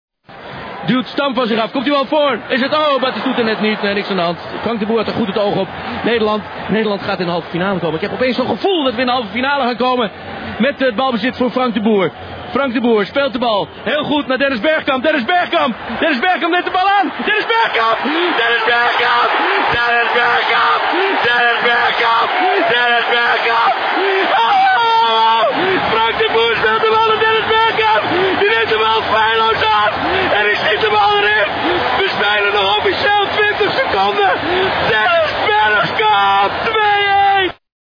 Jack van Gelder reageert lyrisch op de radio,